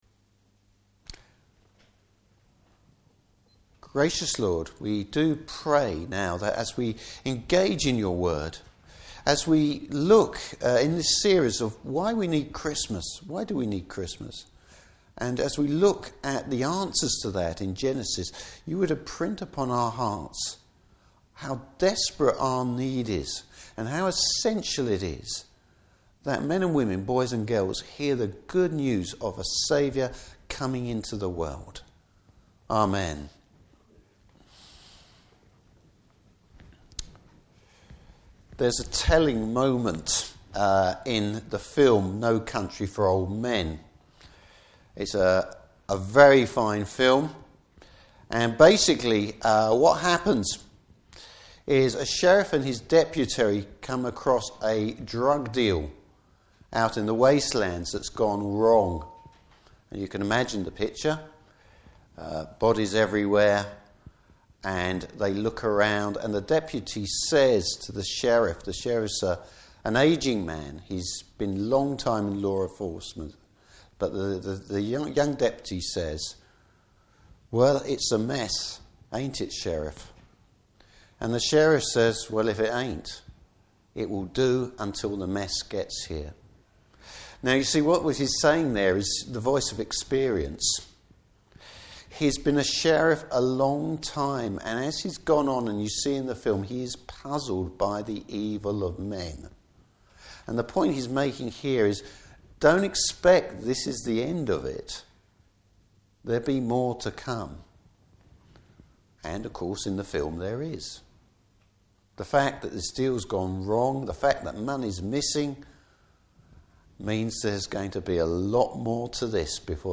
Service Type: Morning Service Bible Text: Genesis 4:1-16.